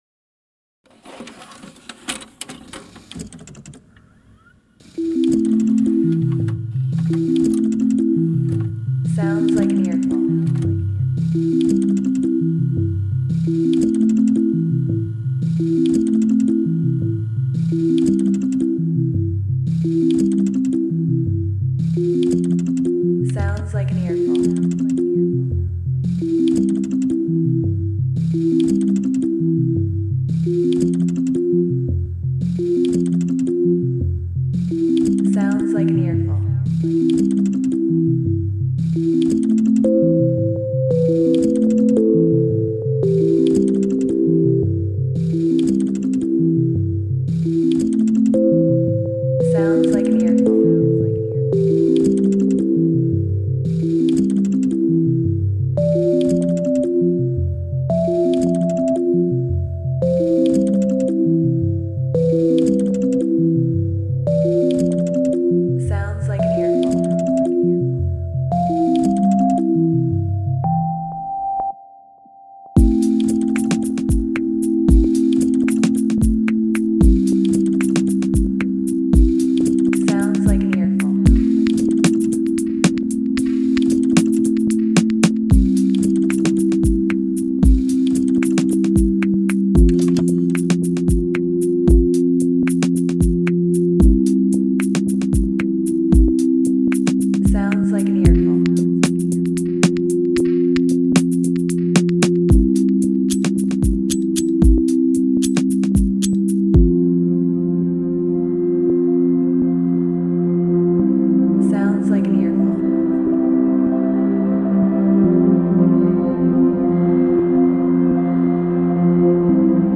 A pensive retro style synth track.